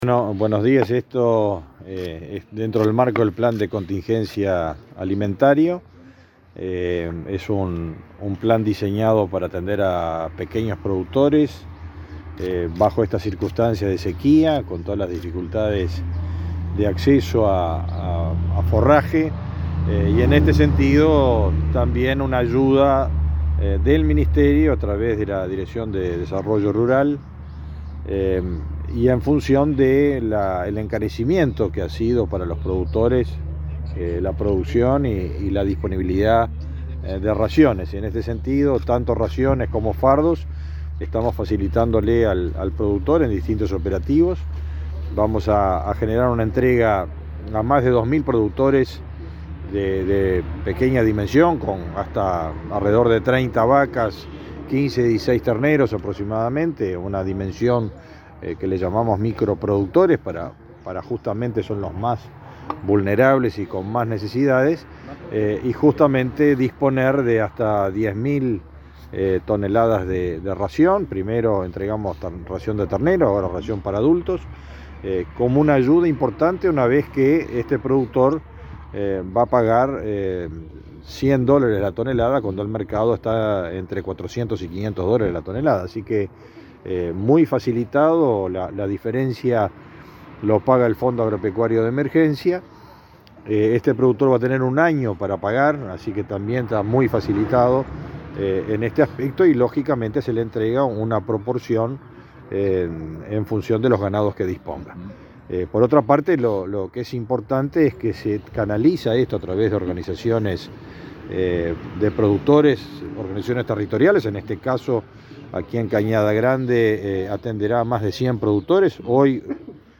Declaraciones del ministro de Ganadería, Fernando Mattos
El ministro de Ganadería, Fernando Mattos, dialogó con la prensa en San José, durante la entrega de ración a productores familiares, en el marco del